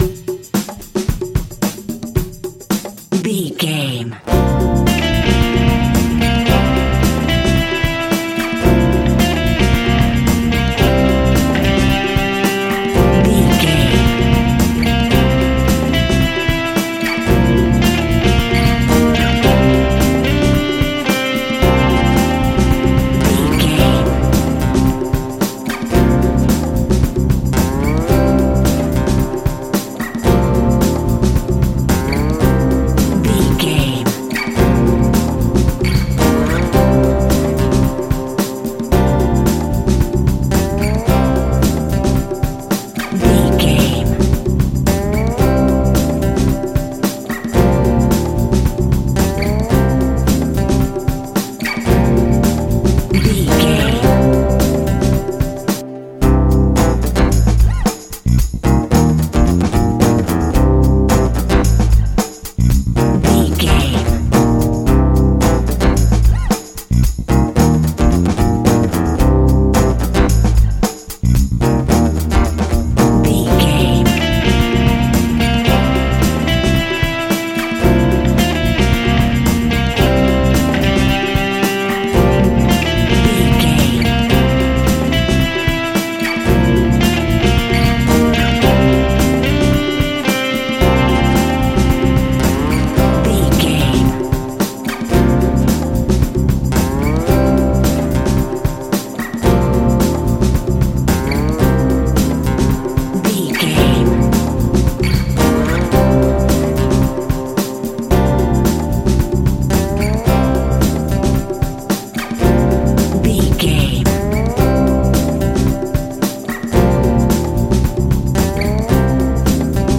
Aeolian/Minor
D♭
cool
uplifting
bass guitar
electric guitar
drums
cheerful/happy